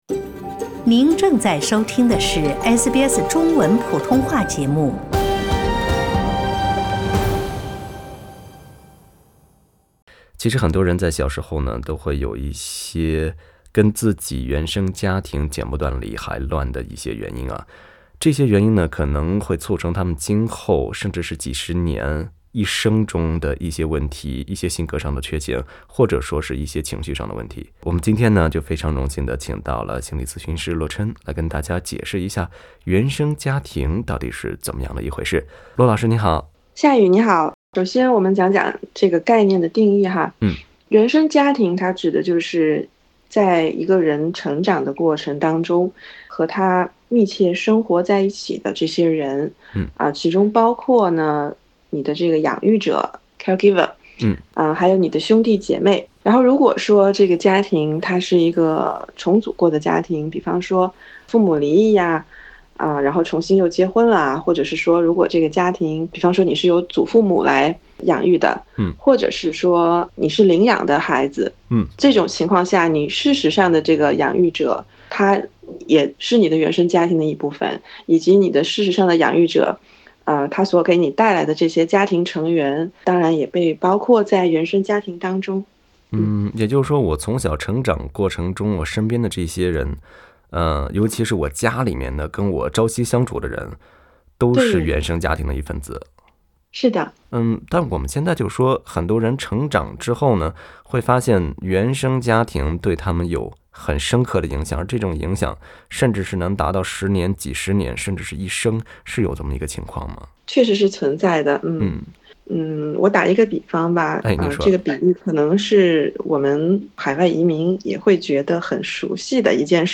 （点击图片音频，收听采访）